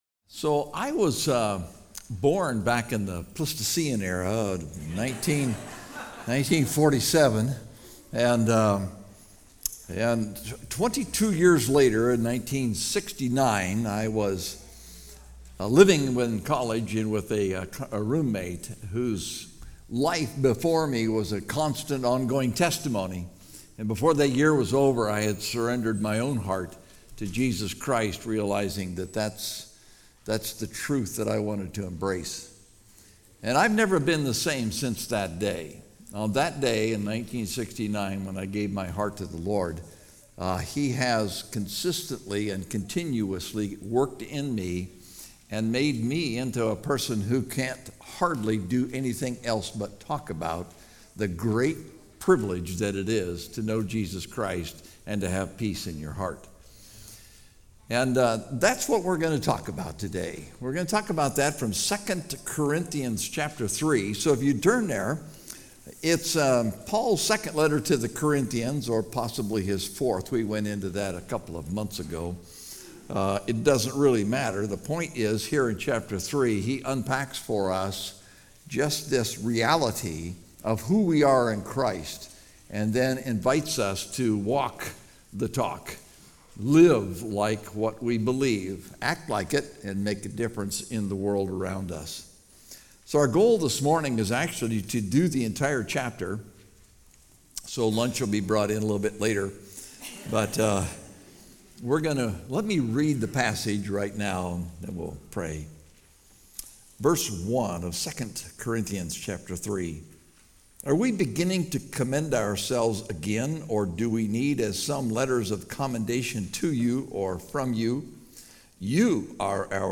Topical Message